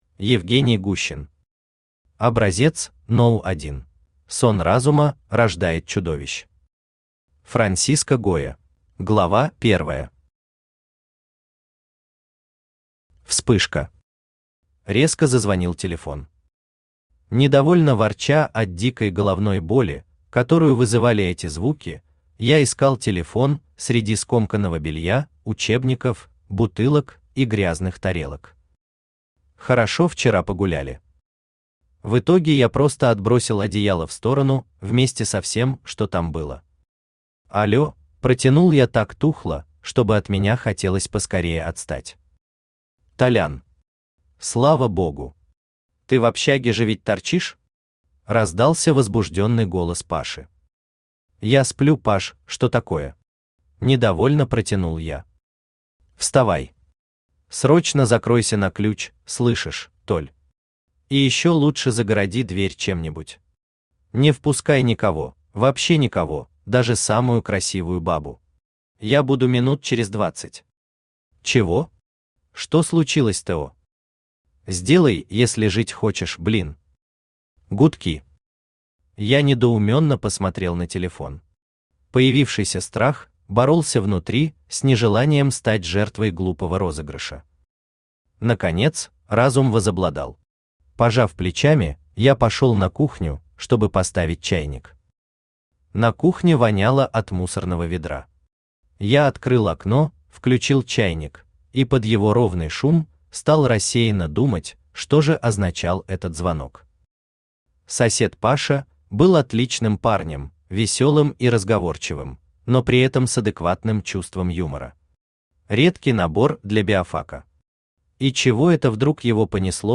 Aудиокнига Образец №1 Автор Евгений Олегович Гущин Читает аудиокнигу Авточтец ЛитРес.